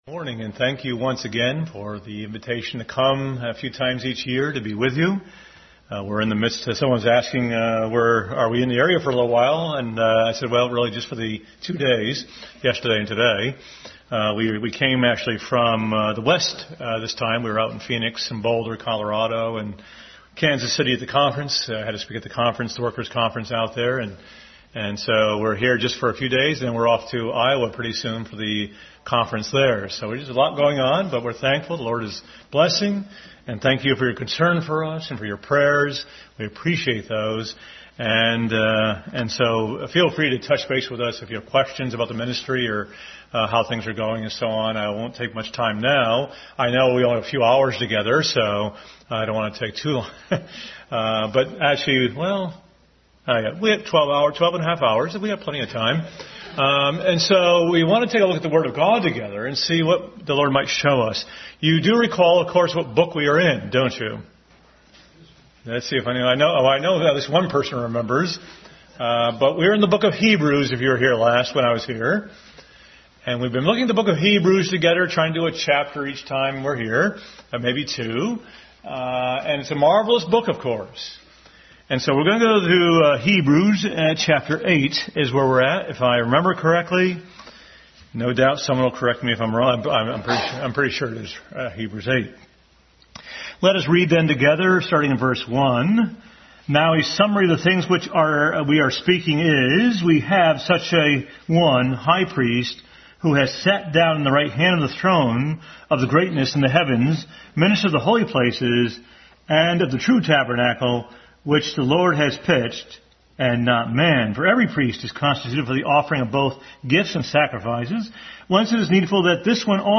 Passage: Hebrews 8 Service Type: Sunday School